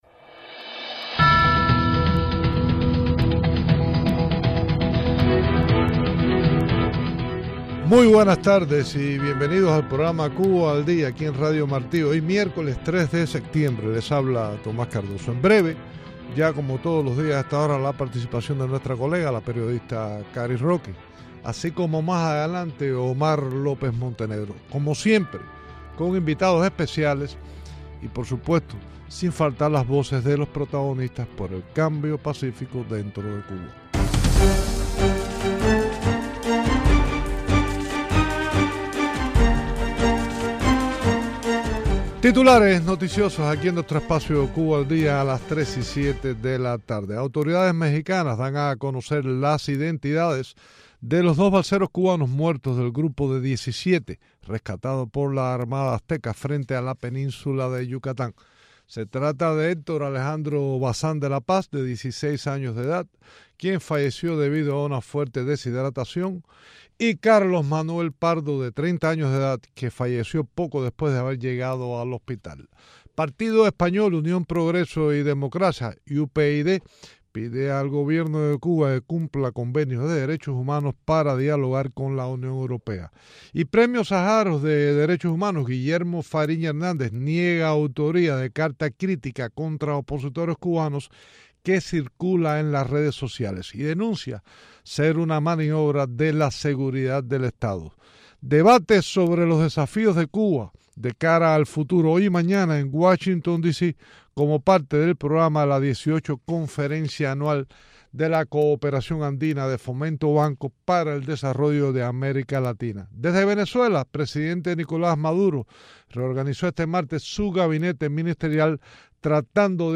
Entrevistas con Myles Frechette